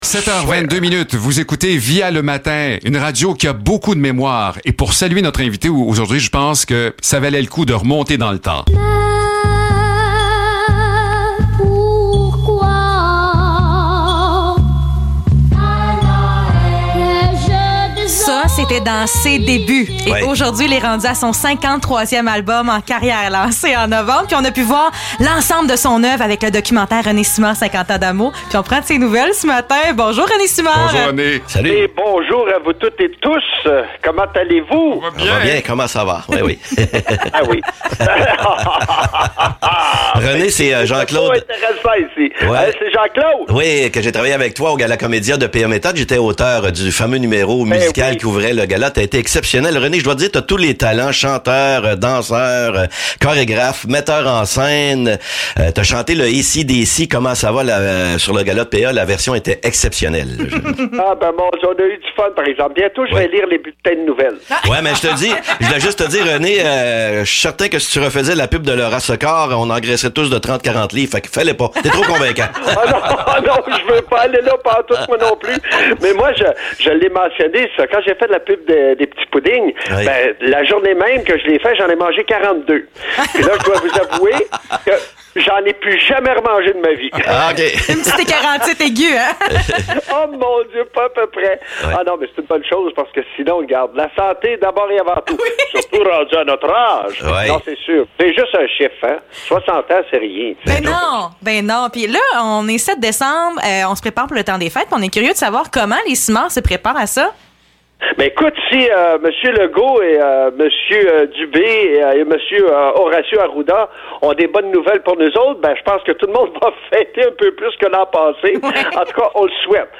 Entrevue avec René Simard (7 décembre 2021)
entrevue-rene-simard-7-decembre-2021.mp3